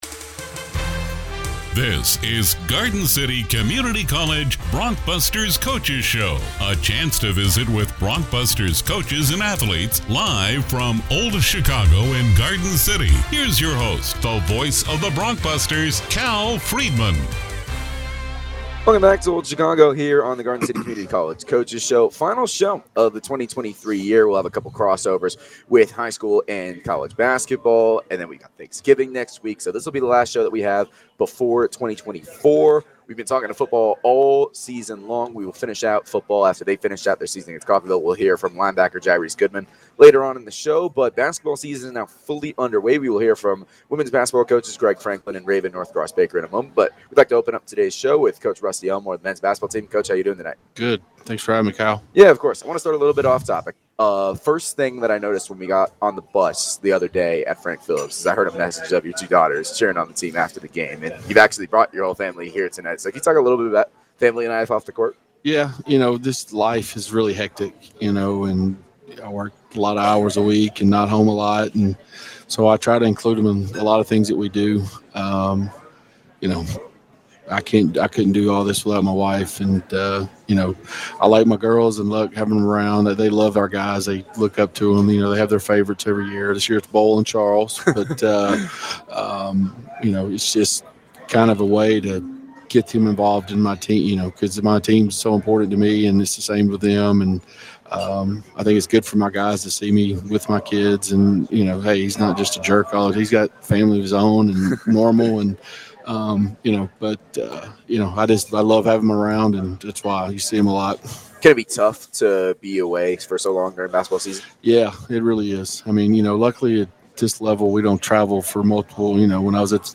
The Garden City Community College Coach’s Show returned from Old Chicago Pizza & Taproom on Thursday night.